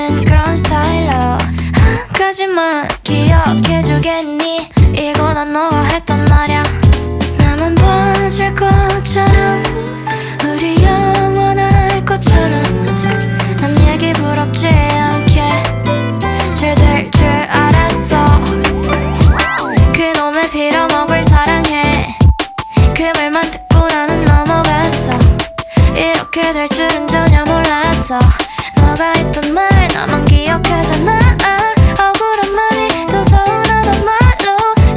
R B Soul Pop K-Pop
Жанр: Поп музыка / R&B / Соул